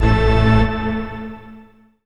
54_32_organ-A.wav